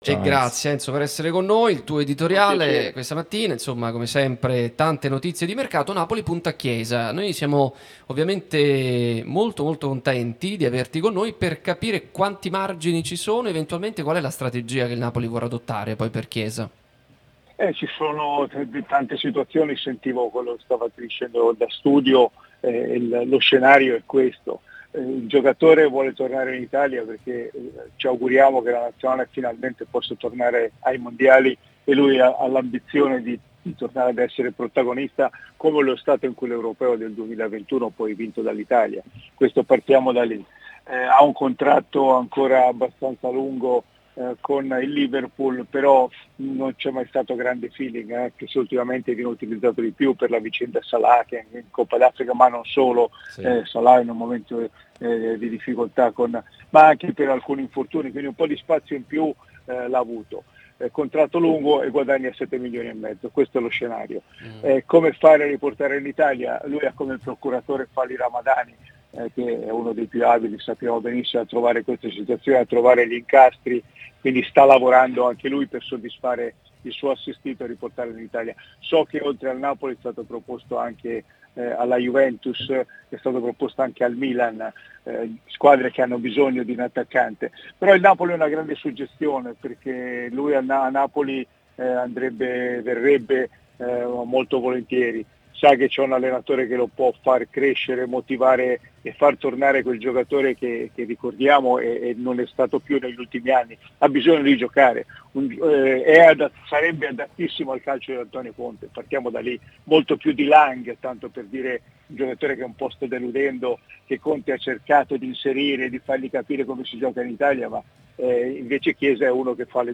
Radio Tutto Napoli
trasmissione sulla nostra Radio Tutto Napoli